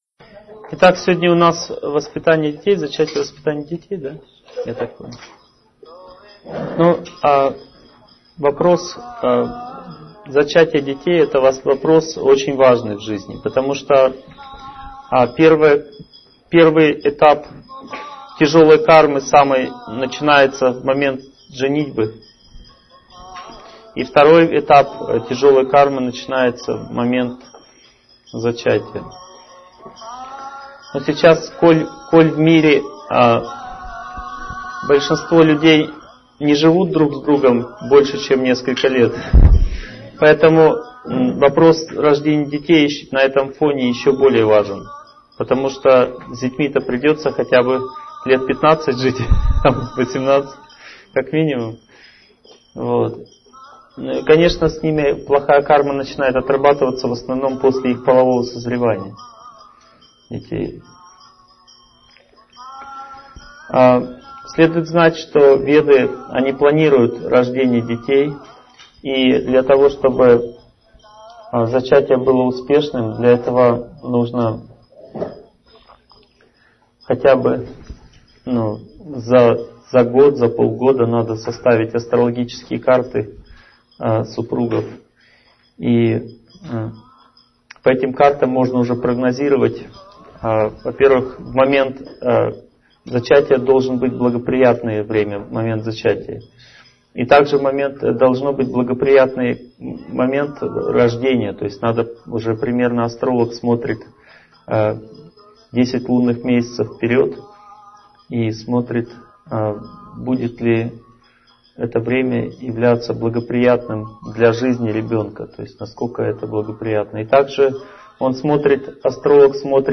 Аудиокнига Правила зачатия и воспитания детей | Библиотека аудиокниг